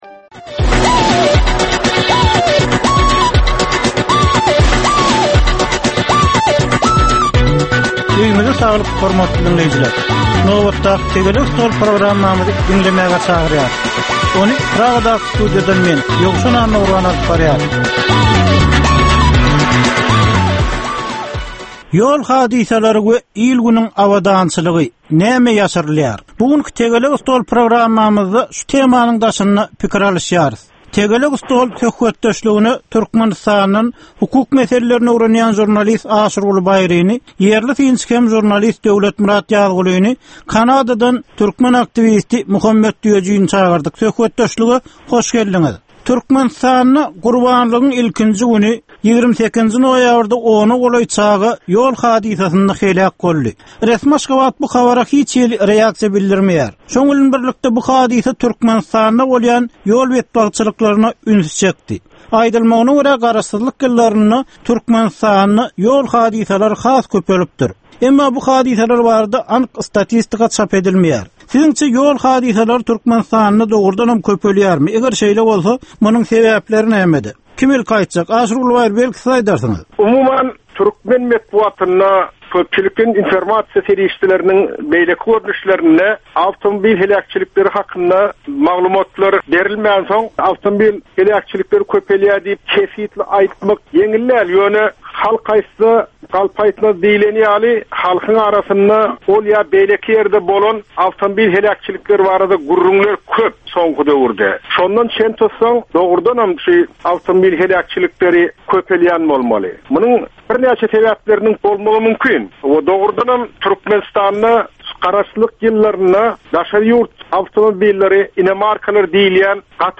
Jemgyýetçilik durmuşynda bolan ýa-da bolup duran soňky möhum wakalara ýa-da problemalara bagyşlanylyp taýýarlanylýan ýörite Tegelek stol diskussiýasy. 25 minutlyk bu gepleşhikde syýasatçylar, analitikler we synçylar anyk meseleler boýunça öz garaýyşlaryny we tekliplerini orta atýarlar.